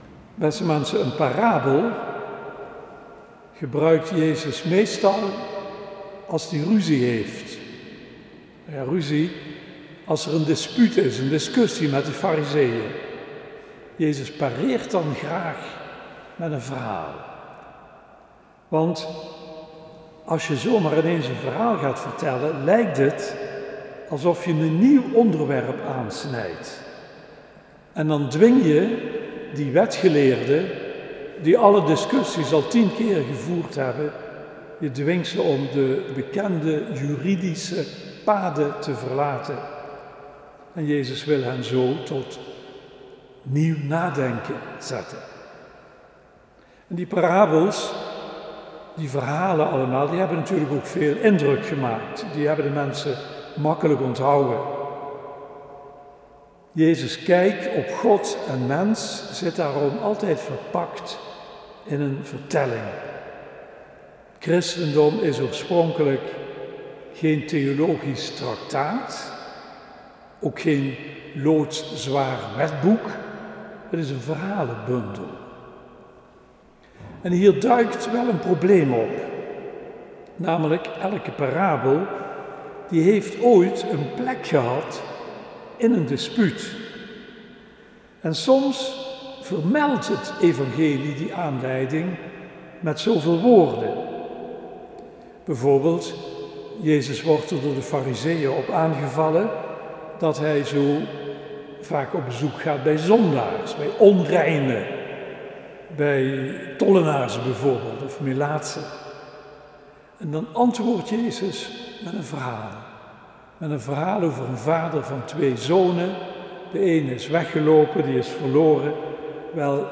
De datum waarop de preek gehouden is ligt gewoonlijk een week later